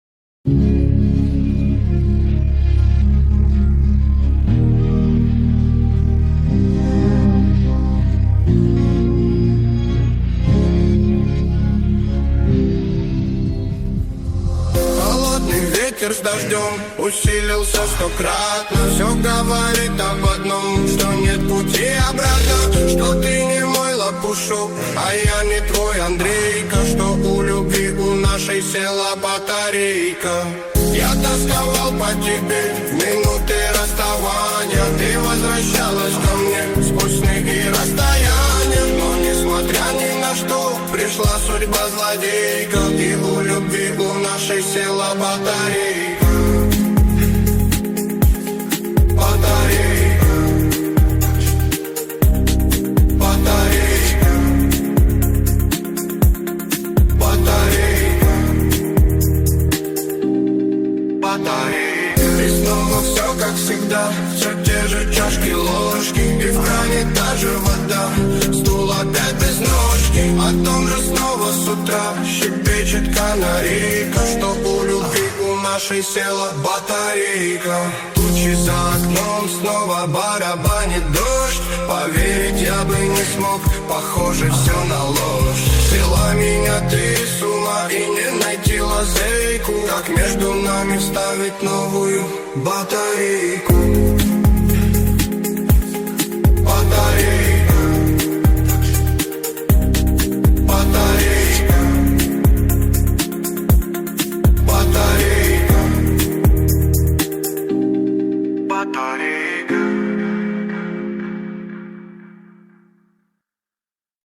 нейросеть